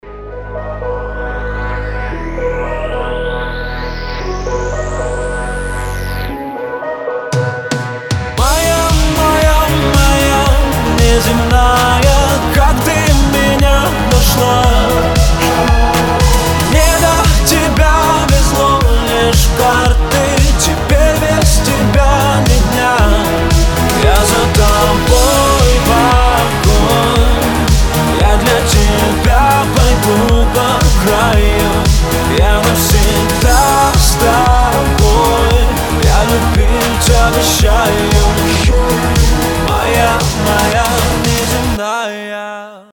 • Качество: 320, Stereo
поп
мужской вокал
Synth Pop